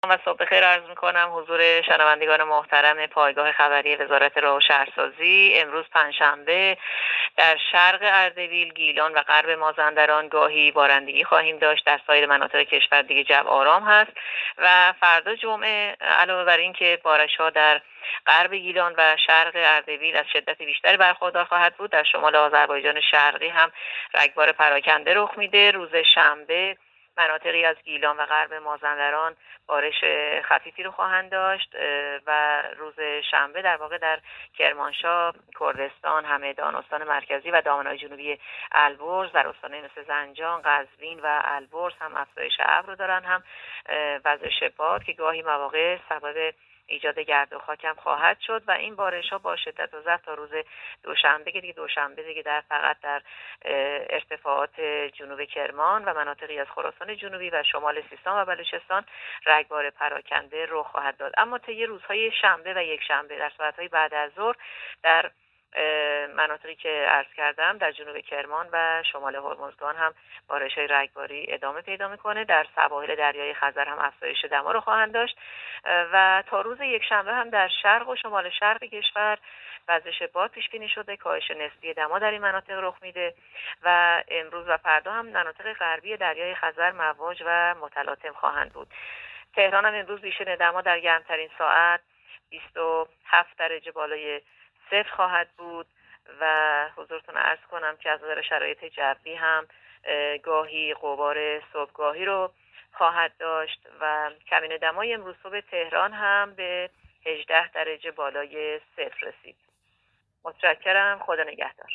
گزارش رادیو اینترنتی پایگاه‌ خبری از آخرین وضعیت آب‌وهوای ۲۴ مهر؛